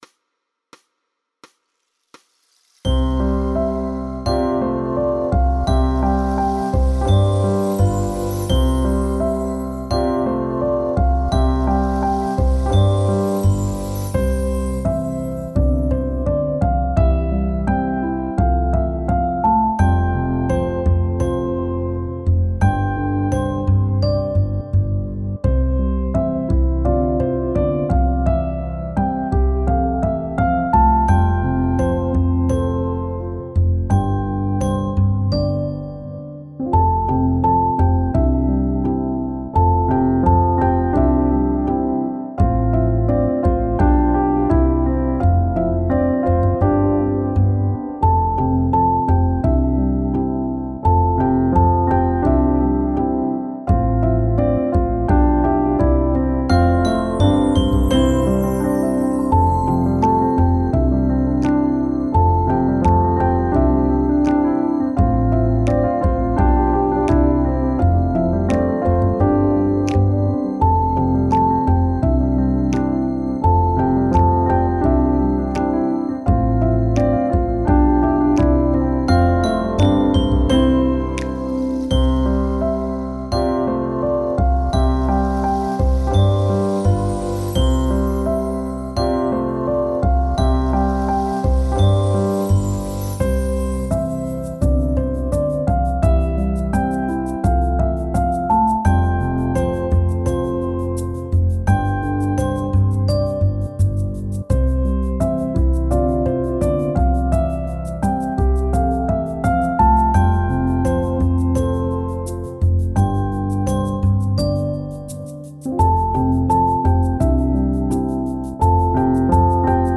co-przyniesie-jesien-podklad-c-85.mp3